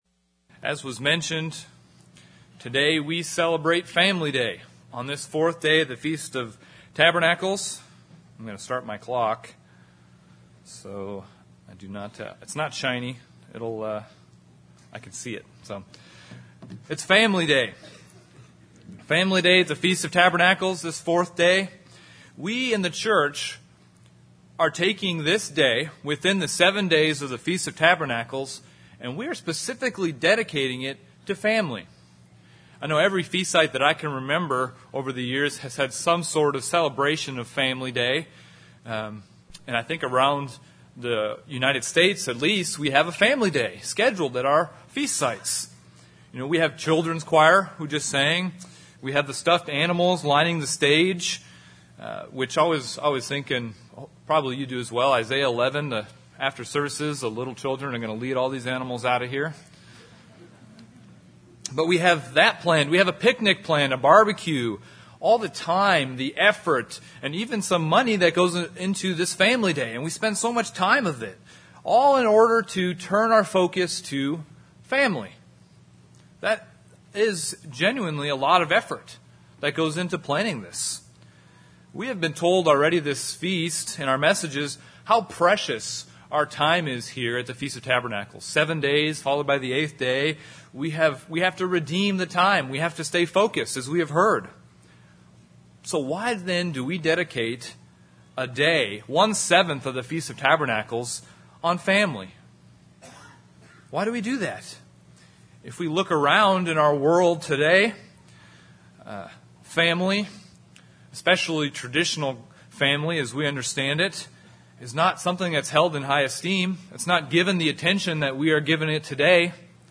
This sermon was given at the Branson, Missouri 2016 Feast site.